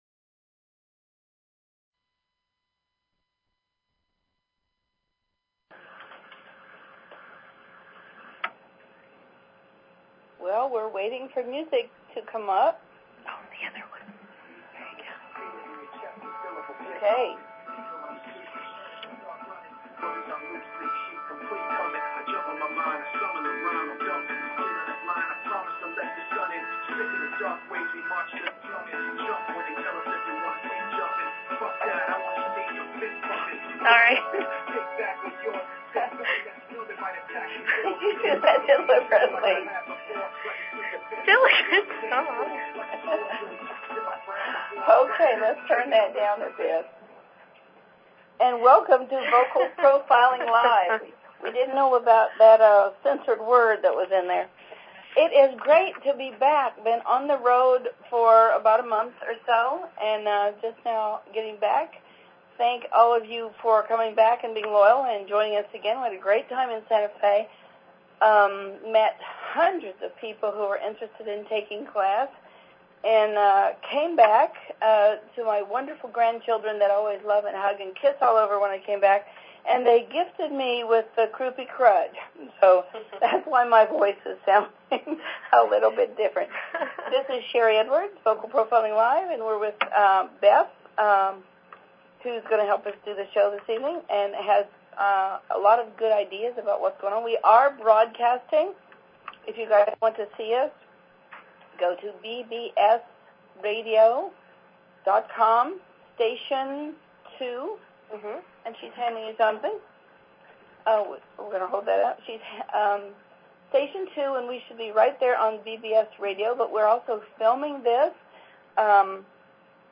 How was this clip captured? There were many laughs in the room as they discussed Bush's quick reflexes and how his words were masking extreme emotions.